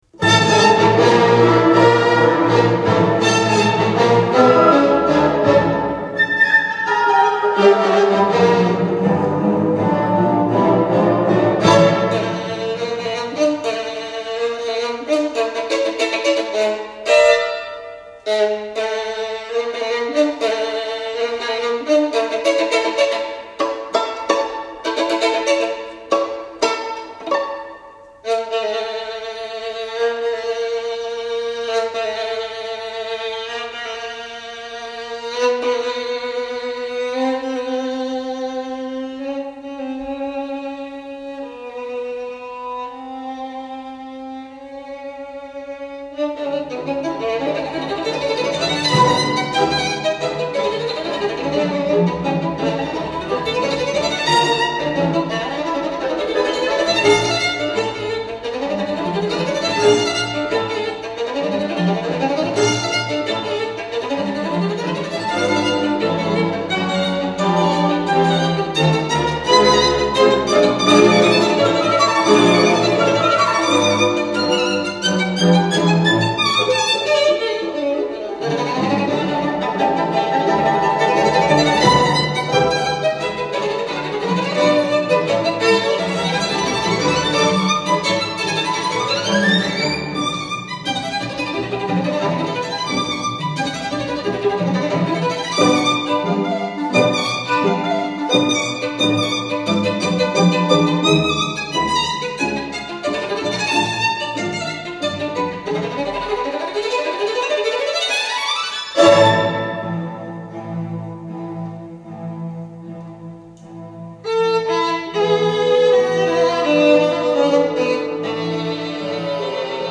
Tchaikovsky - Violin Concerto
Allegro vivacissimo
Tchaikovsky+-+Violin+Concerto.mp3